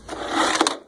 cordinsert.wav